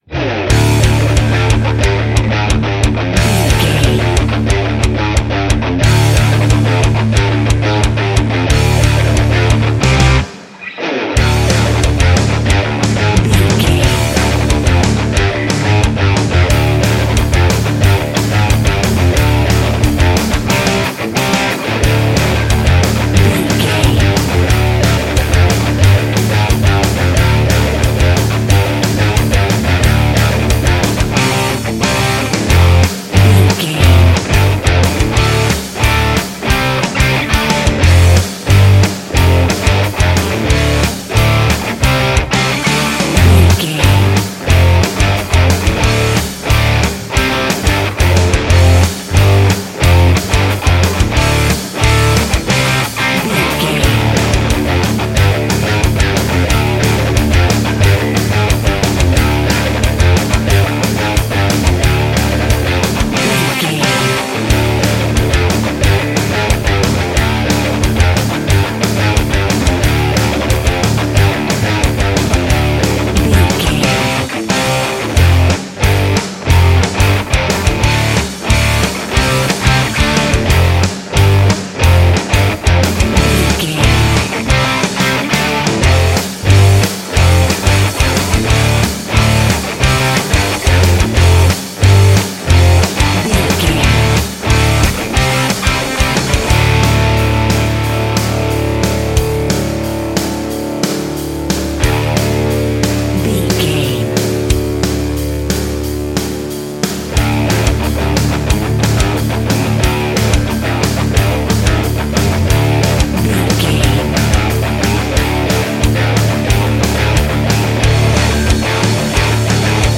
Aeolian/Minor
C♯
Fast
aggressive
intense
driving
dark
bass guitar
electric guitar
drum machine